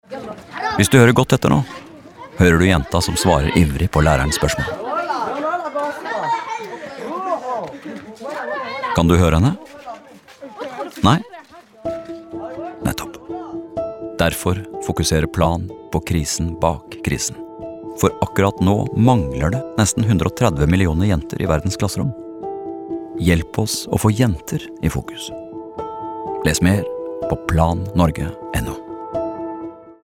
Månedens vinner evner å dra oss lenger inn i radiohøyttaleren for hver gang, og vi regner med at flere lyttere vil spille dem om igjen om de kunne.